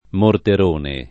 [ morter 1 ne ]